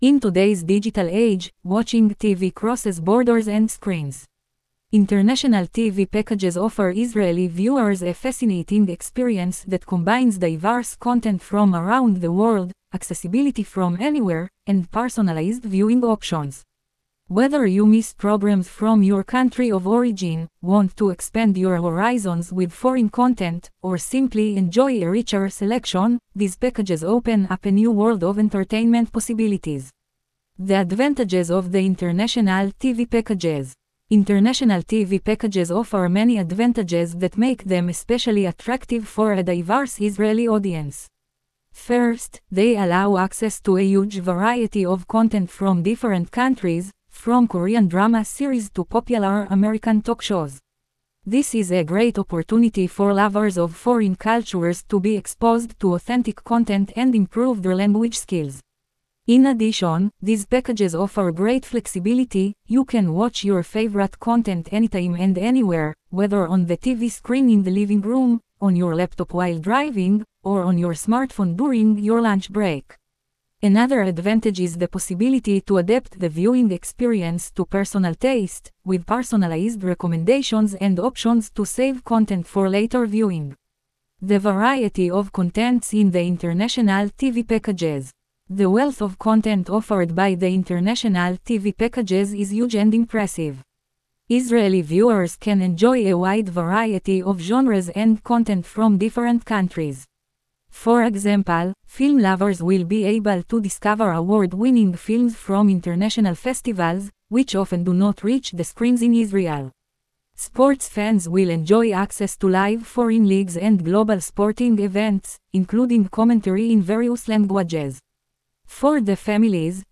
Audio-file-reading-an-article-International-TV-Packages-Not-Just-for-Mobile.wav